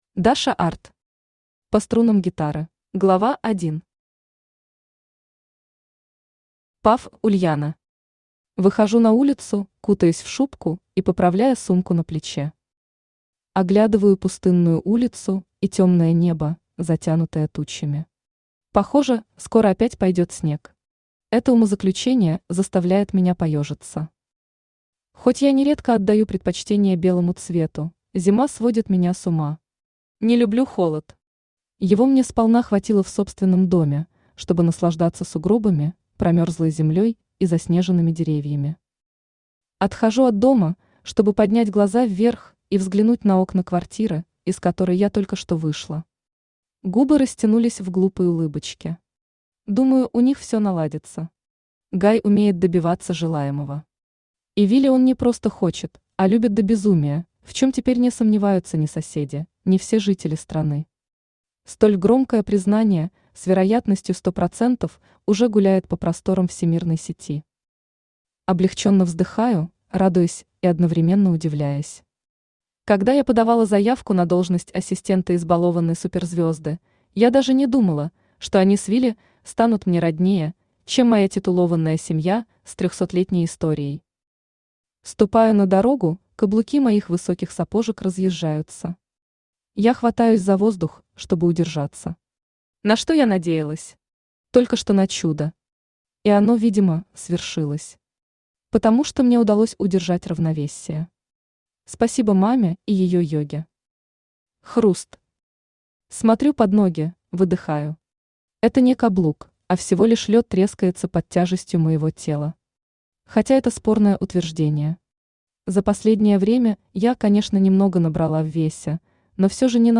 Aудиокнига По струнам гитары Автор Даша Art Читает аудиокнигу Авточтец ЛитРес.